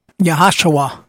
pronunciation audio file
yehhahshuah.mp3